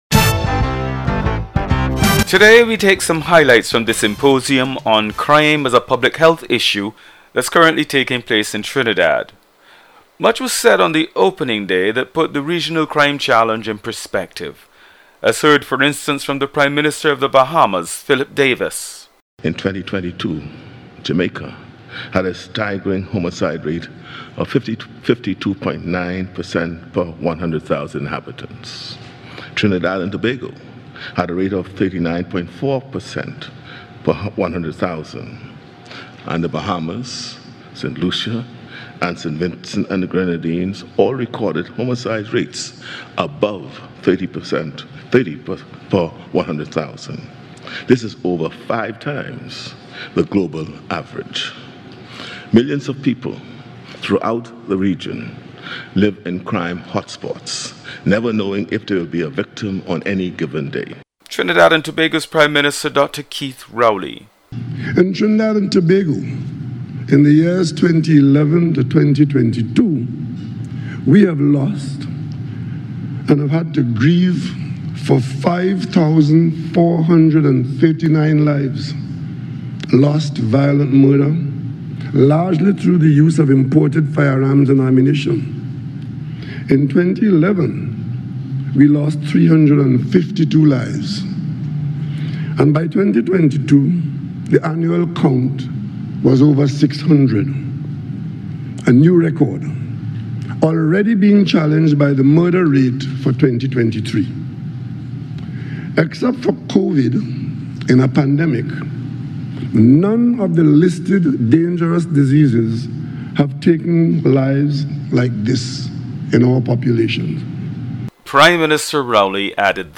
Highlights from the symposium 'Crime is a Public Health Issue' that took place in Trinidad.